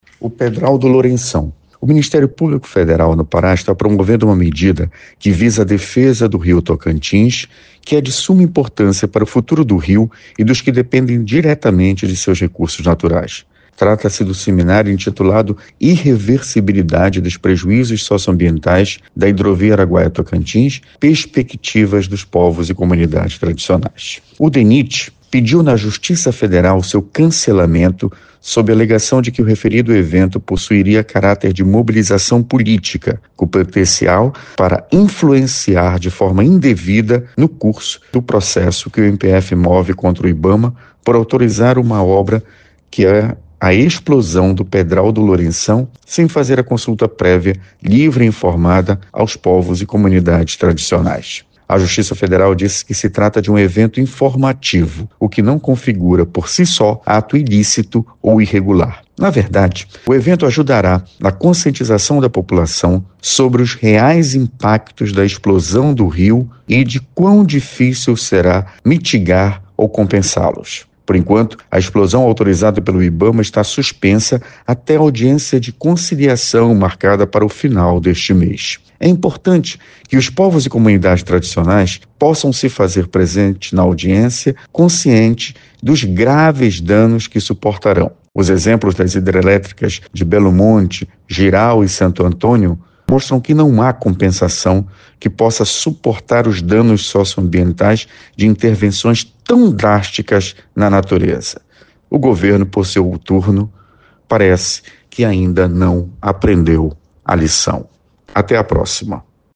Neste editorial o Procurador Regional da República, Felício Pontes, destaca o caso do Pedral do Lourenção. Felício afirma ainda que é importante que os povos e comunidades tradicionais possam se fazer presentes na audiência consciente dos graves danos que suportarão.